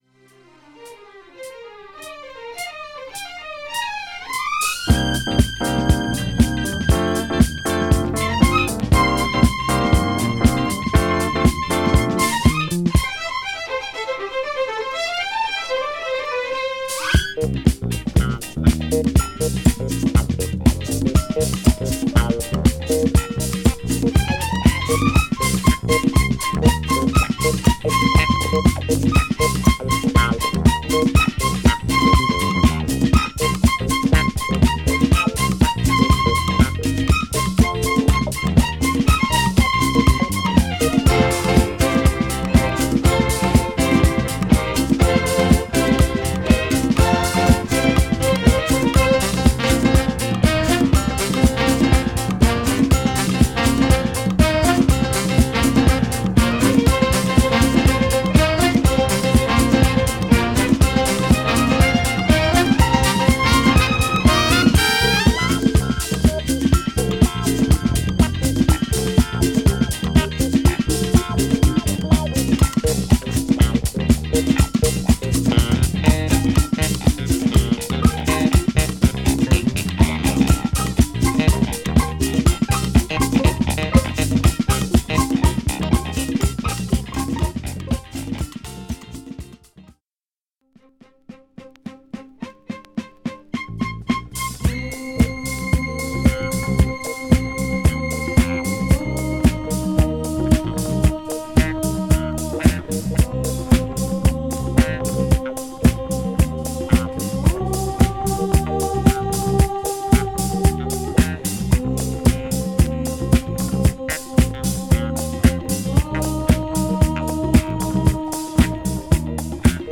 ROCK
案外、7INCHの音は落ち着いてるんですね！！！